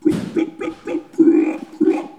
Les sons ont été découpés en morceaux exploitables. 2017-04-10 17:58:57 +02:00 378 KiB Raw Permalink History
bruit-animal_05.wav